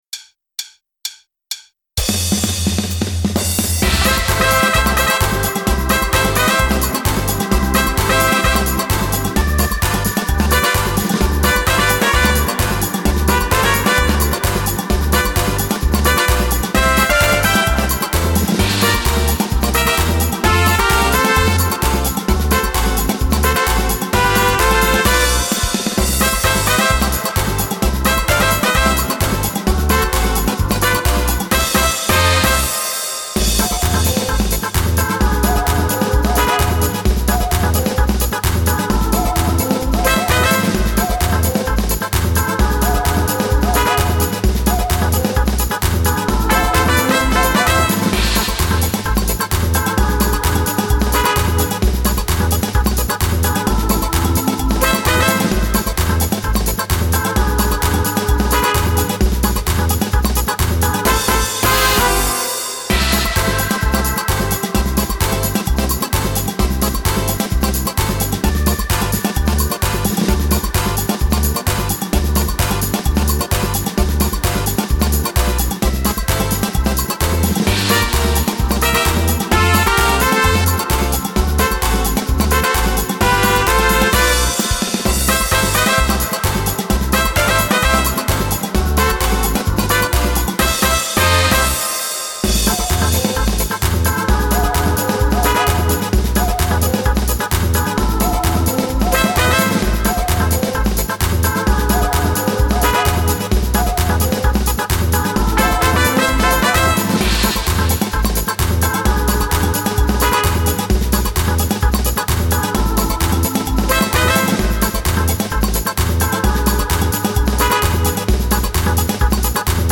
Samba Batucada
Le play- back
rythmique